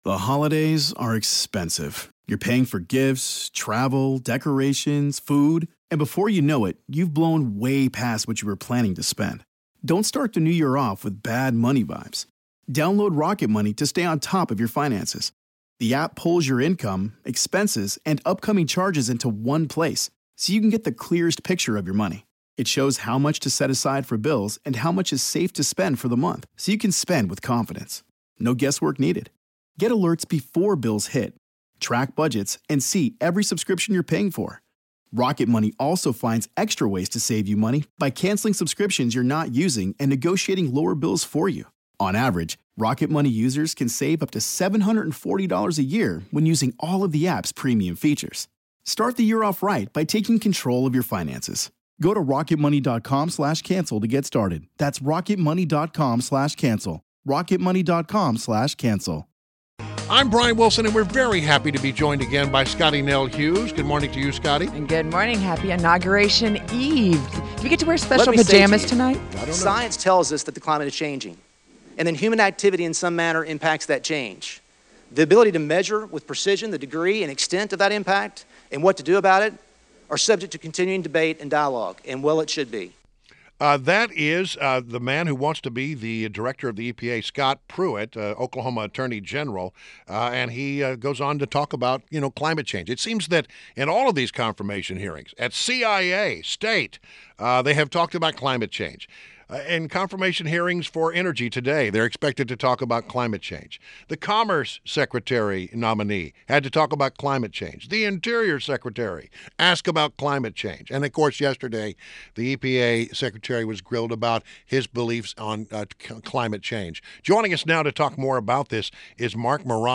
INTERVIEW – MARC MORANO – Climate Depot